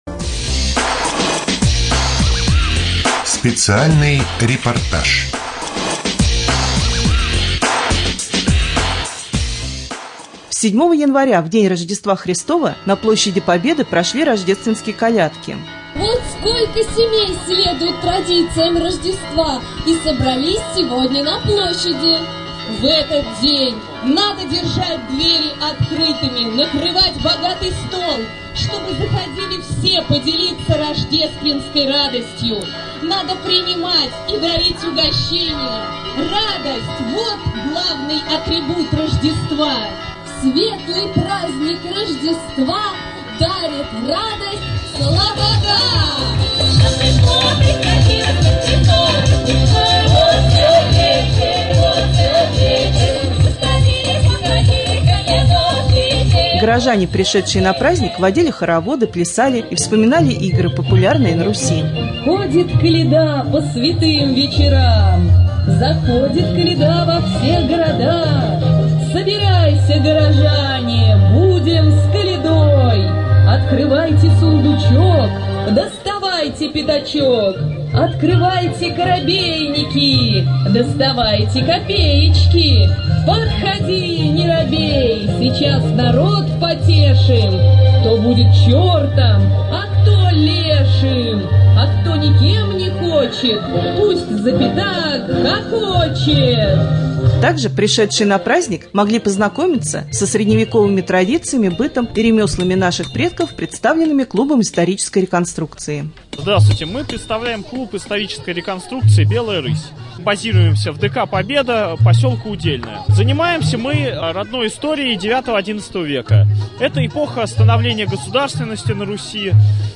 3. Рубрика «Специальный репортаж ». 7 января на площади Победы прошли Рождественские колядки.